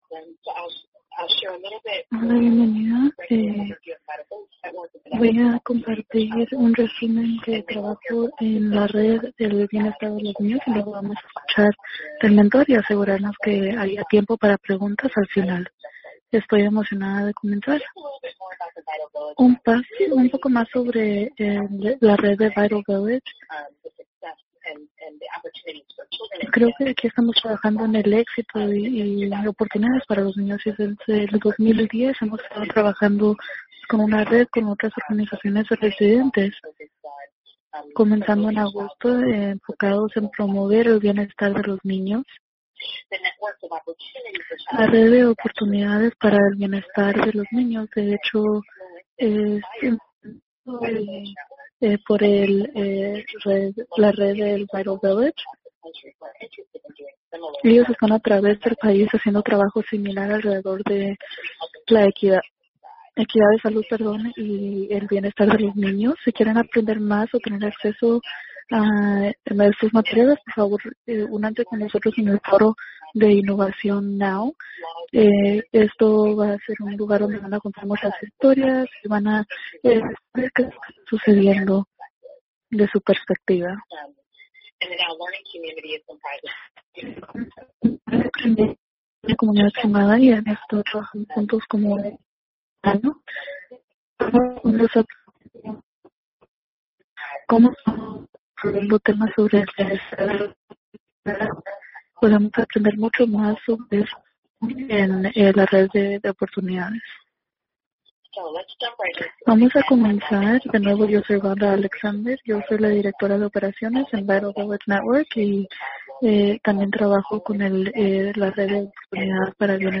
VIRTUAL LEARNING LAB ARCHIVE
NOTE: Due to technical issues, the Spanish audio recording of the live interpretation contains a few gaps in audio.